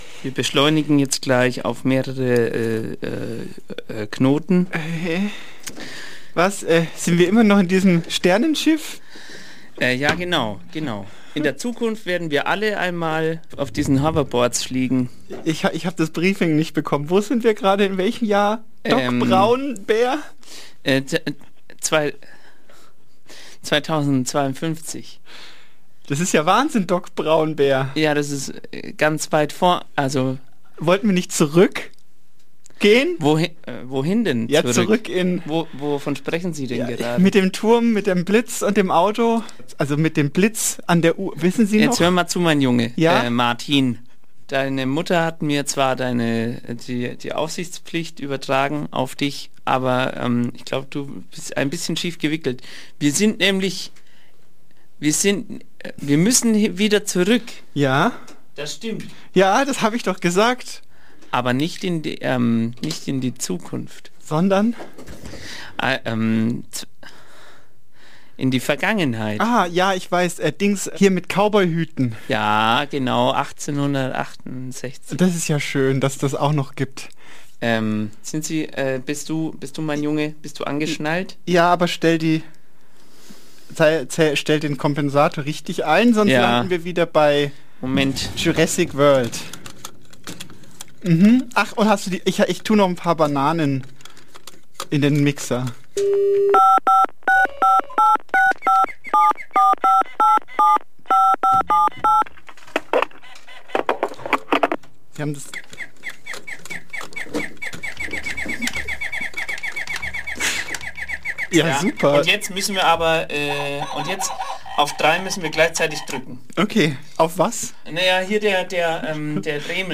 Kabarett
Talk